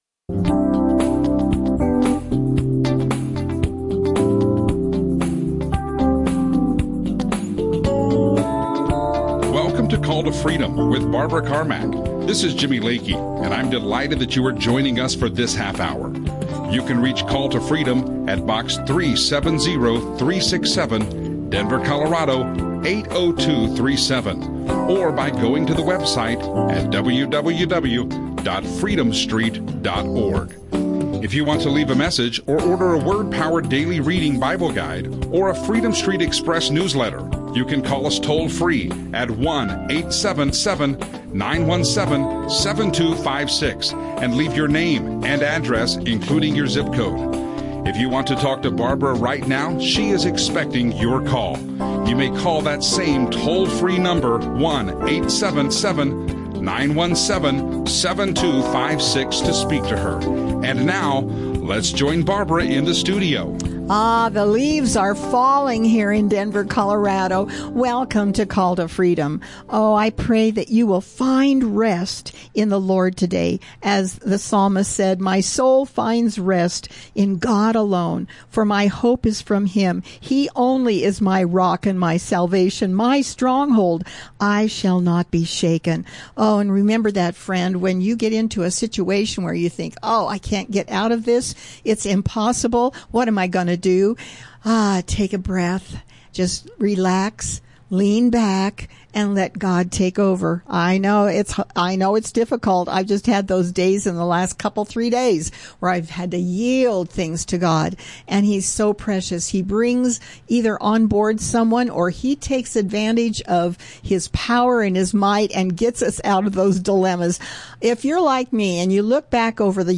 Christian talk
radio show